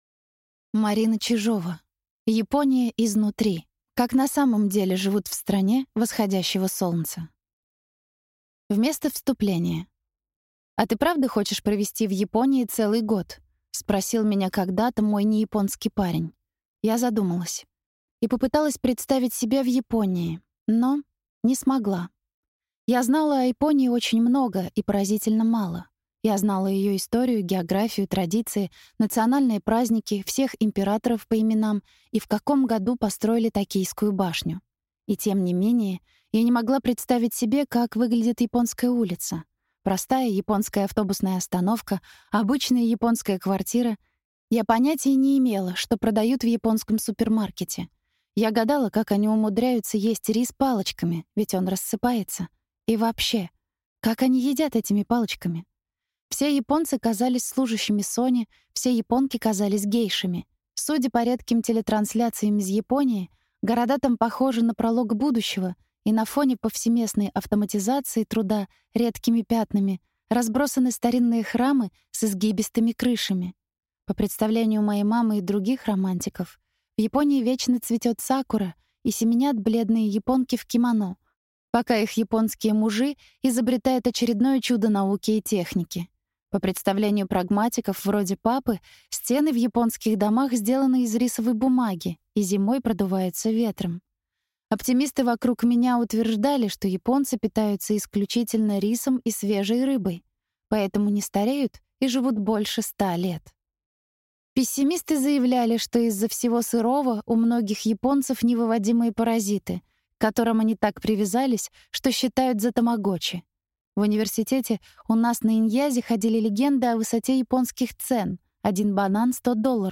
Аудиокнига Япония изнутри. Как на самом деле живут в стране восходящего солнца?